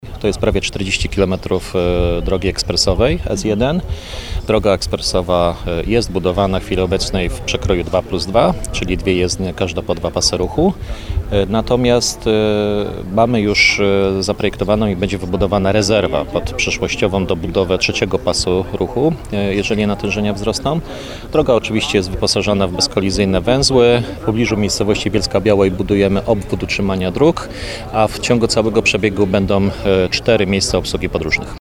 W Dankowicach, w sąsiedztwie placu budowy odcinka drogi S1, odbyła się konferencja prasowa.